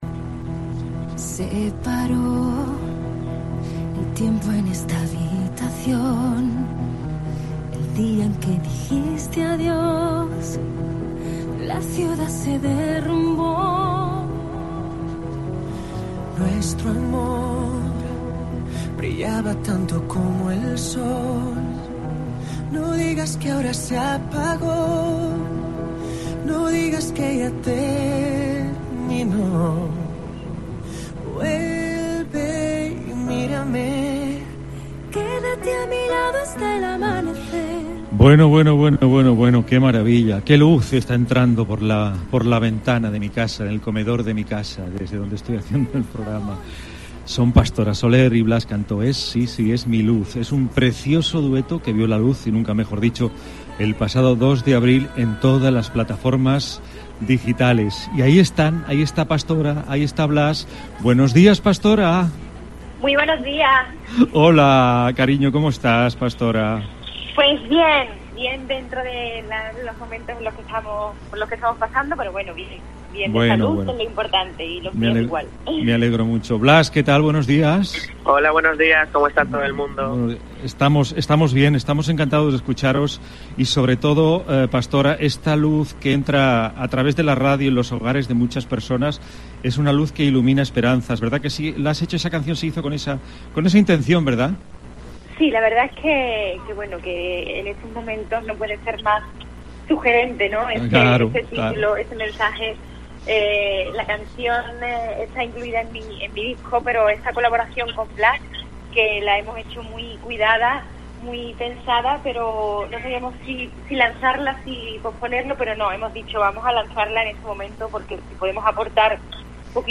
Con ellos dos hemos querido conversar en "Migdia a Cope Catalunya i Andorra".